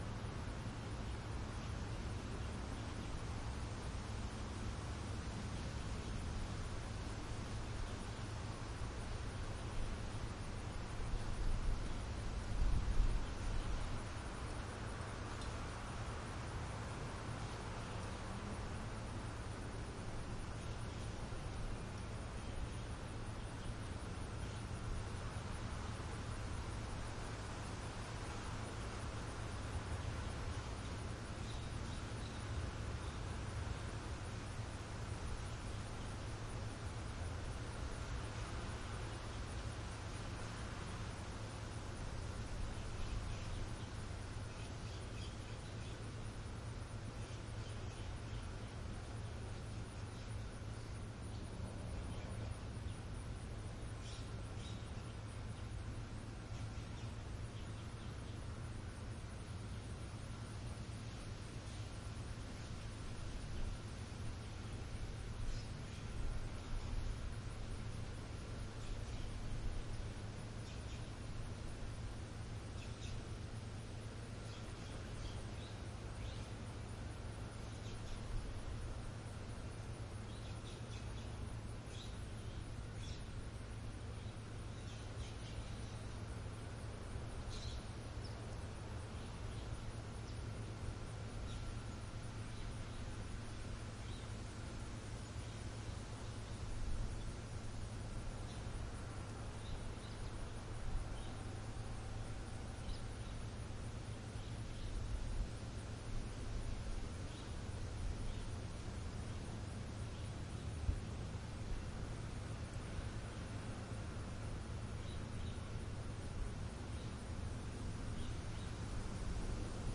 描述：树丛中一大群鸟儿的声音：我们听到鸟儿如何在很高的水平上啁啾。此外，我们可以听到其他声音在城市公园中是正常的，如儿童，狗，摩托车...... 用Zoom H4n录音机录制。
标签： 羊群 啁啾 公园
声道立体声